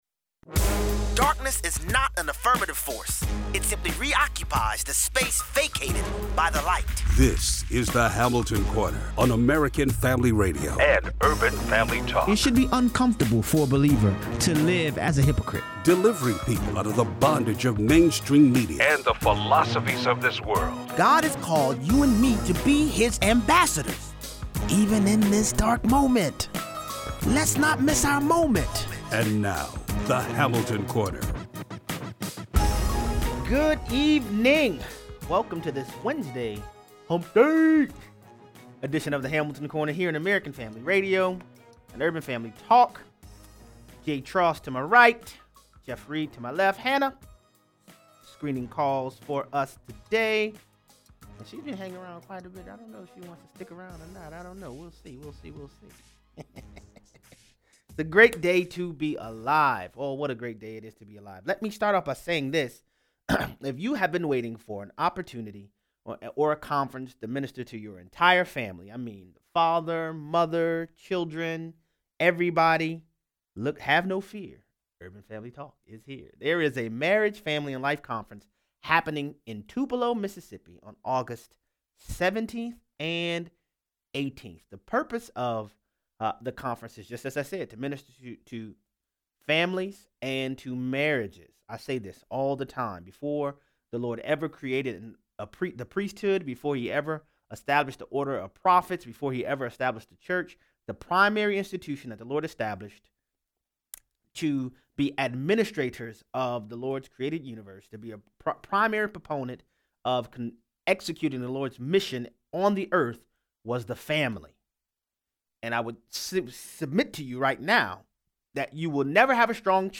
Mainstream media quickly goes into the spin cycle. Sex in the City cleverly packaged a sinful lifestyle which leaves people broken and alone. 0:43 - 0:60: When others don’t understand the glorious workings of marriage they will attempt to inject negative counsel into your life. Callers weigh in.